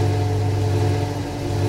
scpcb-godot/SFX/Ambient/Room ambience/fuelpump.ogg at d1278b1e4f0e2b319130f81458b470fe56e70c55
fuelpump.ogg